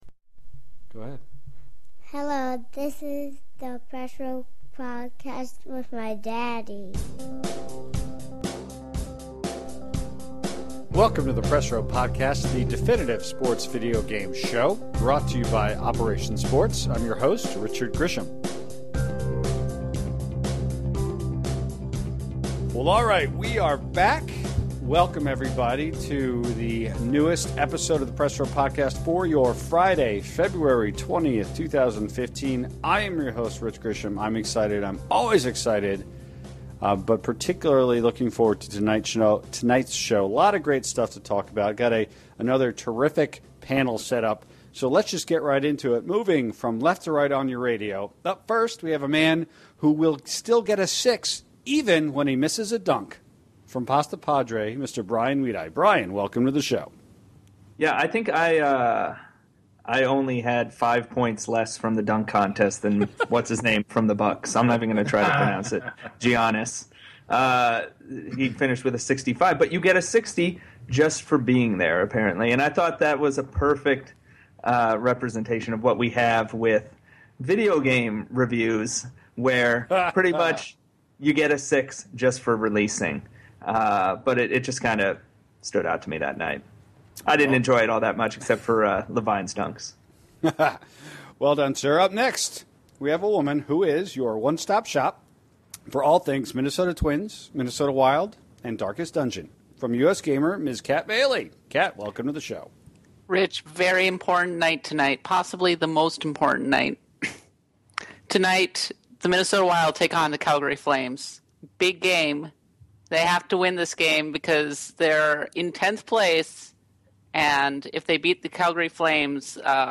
It’s the 122nd episode of the Press Row Podcast, featuring a near-complete panel diving into two significant topics. Up first, the crew examines how Diamond Dynasty seems to (so far, at least) be a significant focus for MLB 15 The Show.